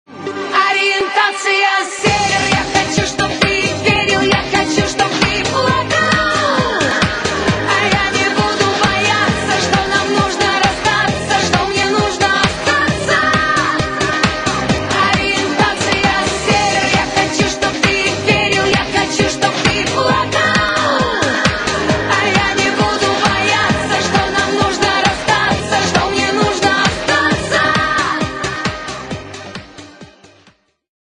Категория: Попса